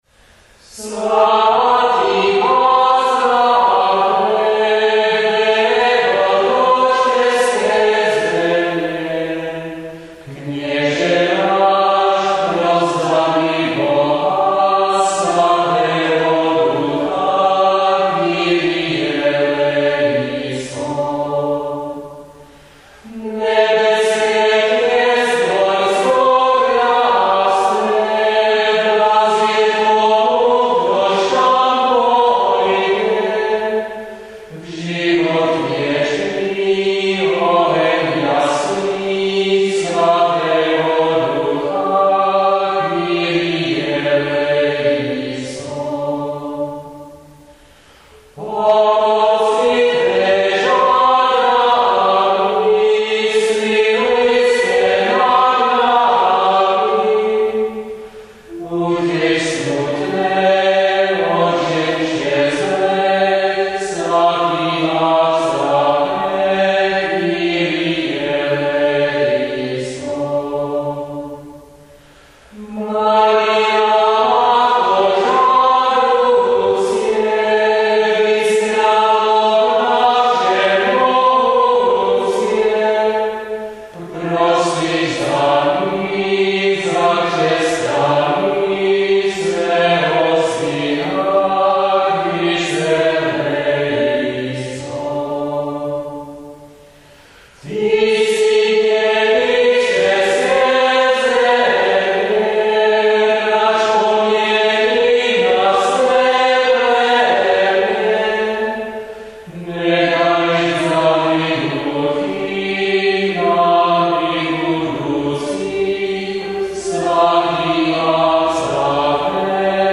chorál v liturgii
Ukázky nazpívala spojená schola